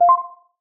10. notification4